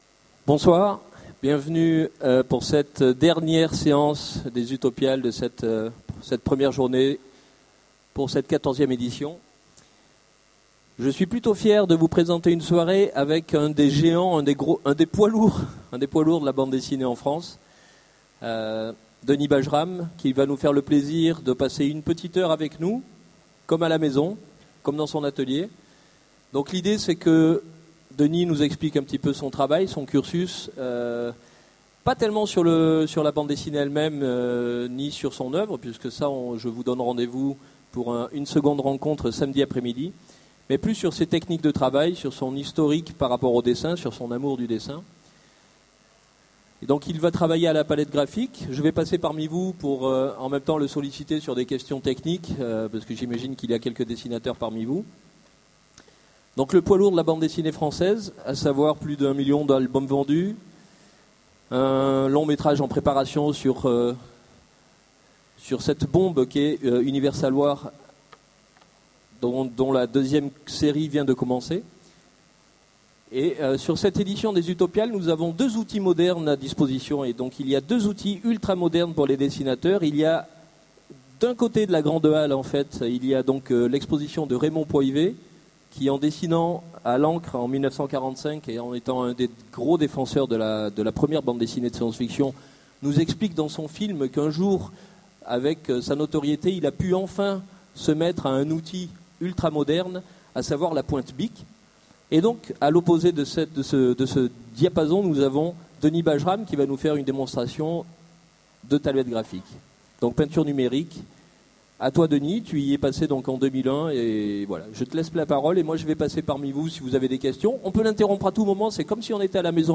Utopiales 13 : Workshop avec Denis Bajram
- le 31/10/2017 Partager Commenter Utopiales 13 : Workshop avec Denis Bajram Télécharger le MP3 à lire aussi Denis Bajram Genres / Mots-clés Illustration Rencontre avec un auteur Conférence Partager cet article